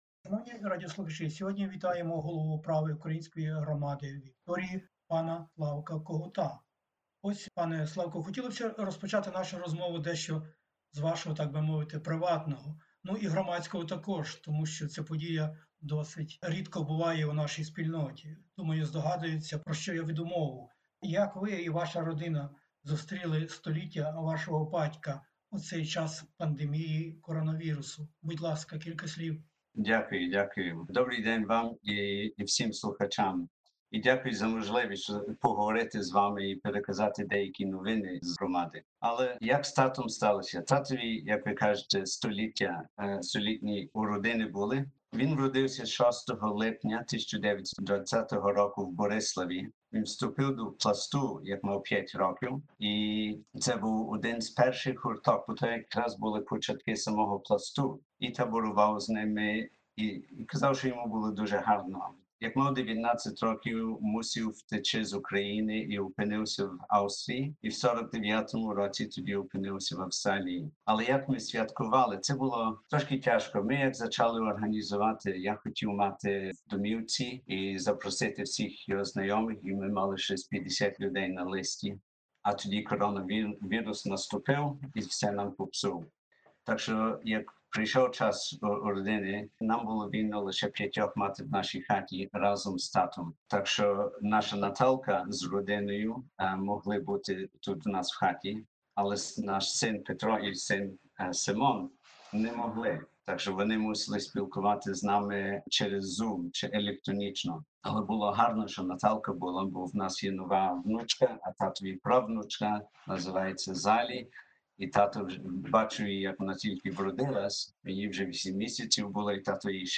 У радіопрограмі SBS Ukrainian - розмова